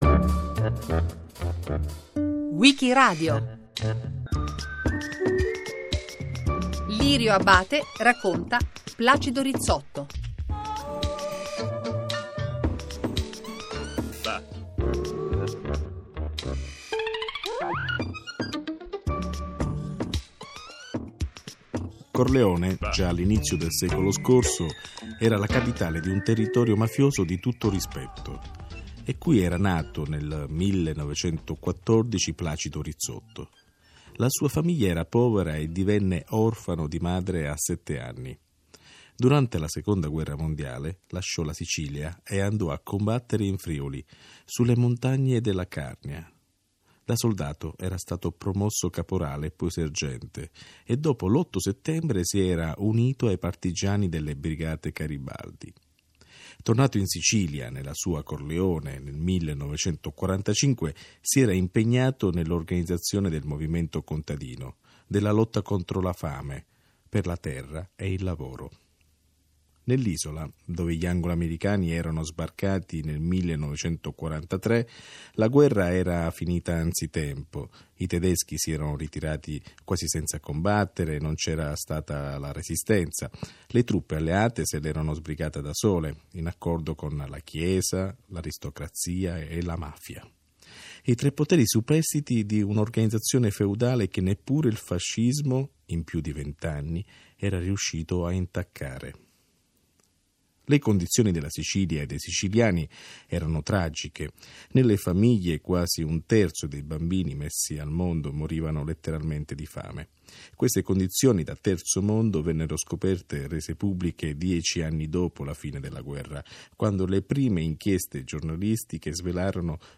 Convegno di studi sulle prospettive del movimento socialista europeo, organizzato nell’ambito delle celebrazioni per il 120° anniversario della fondazione del Partito socialista italiano, tenutosi a Roma presso l’Istituto della Enciclopedia Italiana il 29-30 novembre 2012.